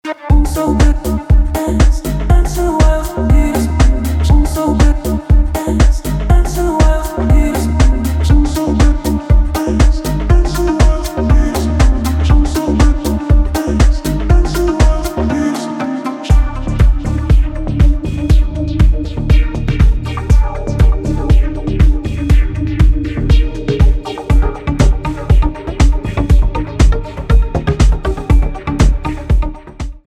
электроника
басы , танцевальные